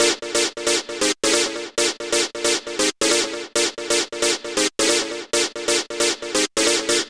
K-7 Stab.wav